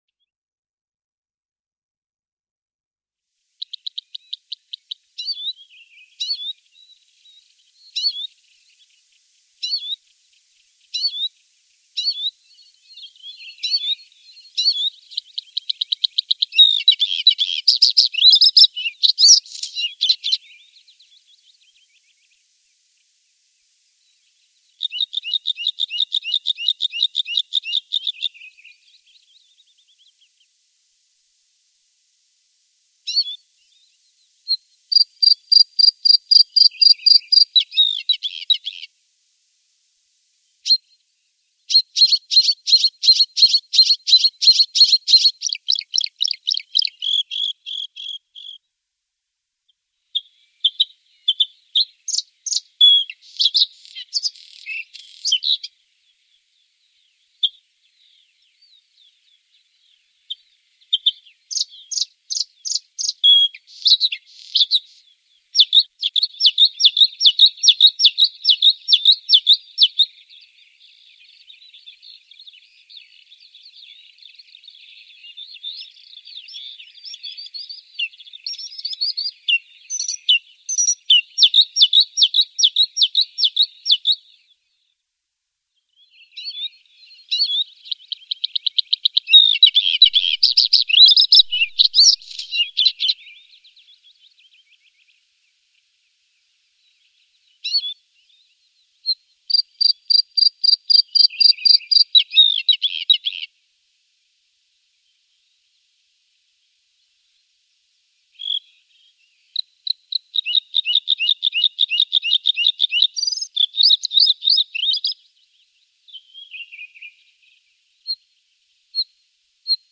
Braunkehlchen